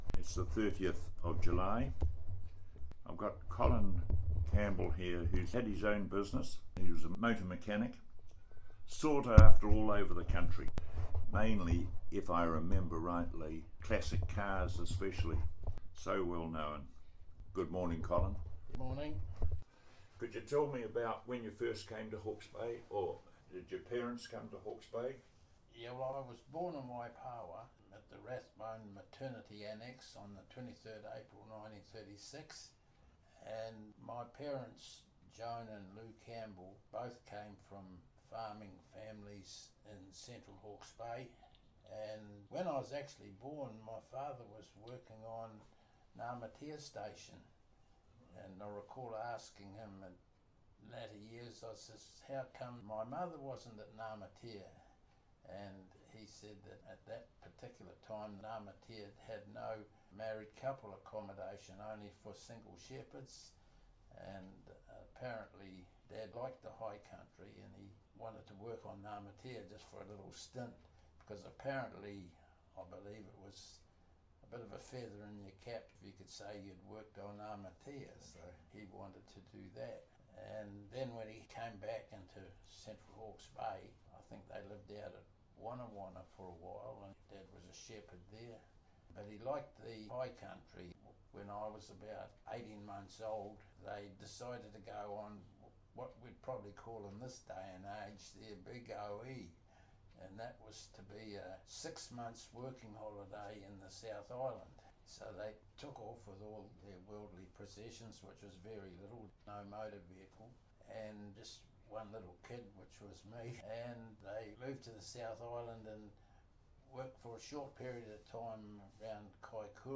This oral history has been edited in the interests of clarity.